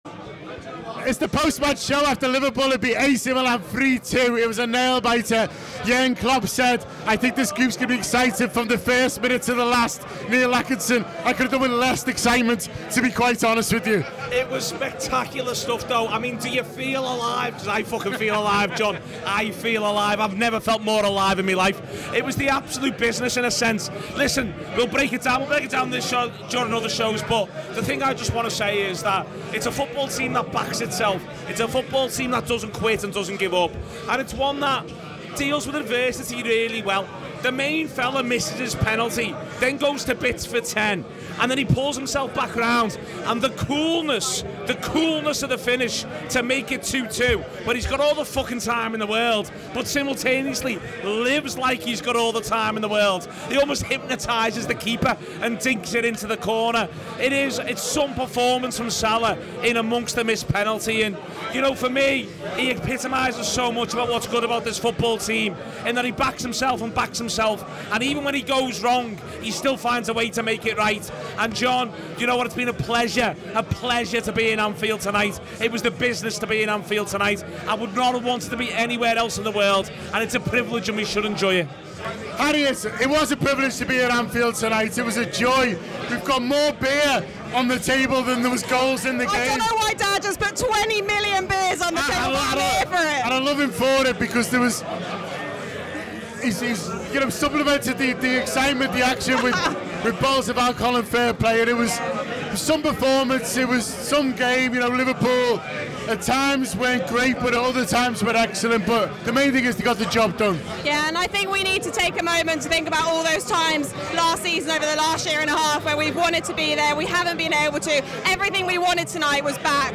Twenty million beers are on the table, experience the complete joy and the full spectrum of mad emotions as our contributors reflect on the match.